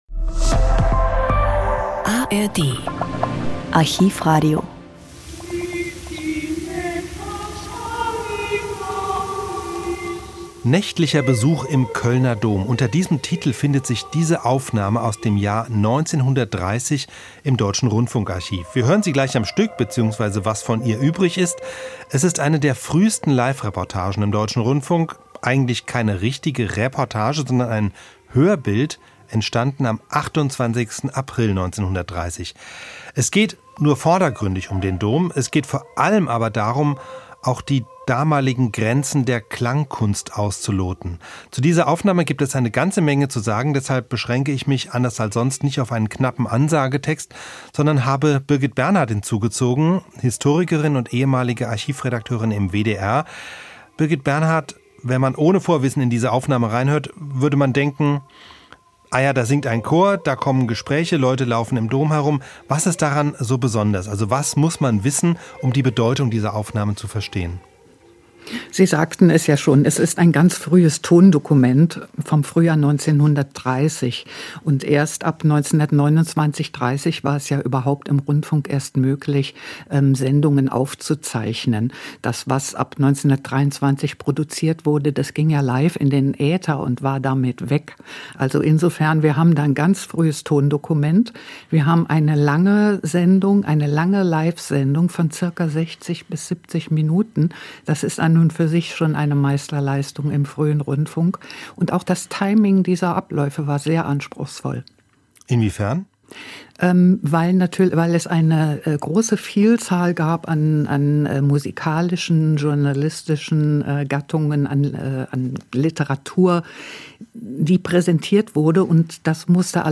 fruehe-live-reportage1930-naechtlicher-besuch-im-koellner-dom.m.mp3